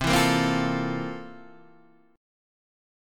C 13th